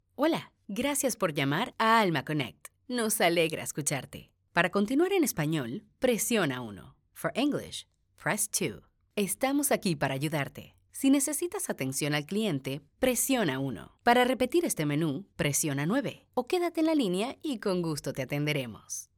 Professional On Hold Phone Message | Business Phone Greetings & Recordings
Dulce y cercana. Amable, sensual y rotunda.
Gracias a la interpretación, la voz puede ser cercana y cariñosa o rotunda y dura, dependiendo de la necesidad del texto....